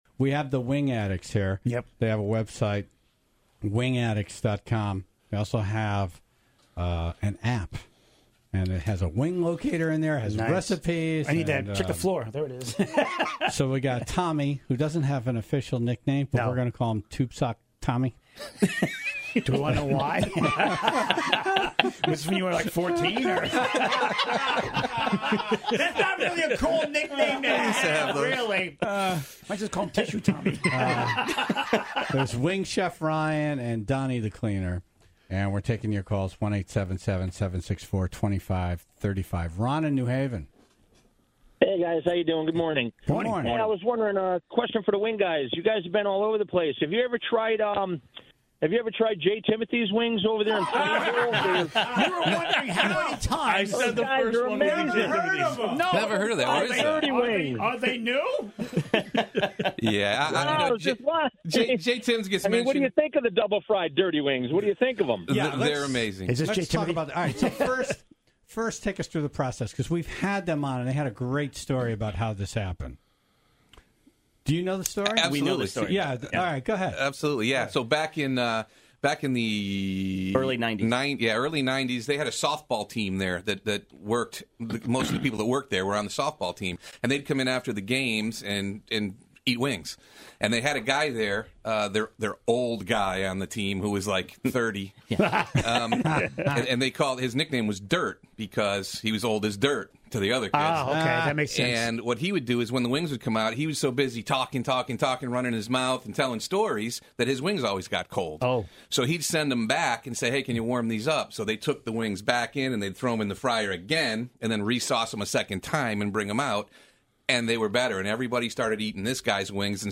The Wing Addicts were in studio